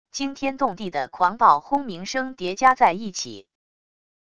惊天动地的狂暴轰鸣声叠加在一起wav音频